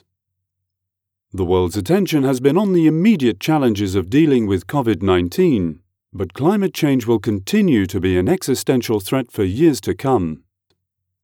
Male
Adult (30-50), Older Sound (50+)
Bold, natural English / British RP with a range of accents from young to old.
'A convincing, voice with intelligent delivery that will engage, educate, entertain or inspire'
Political Spots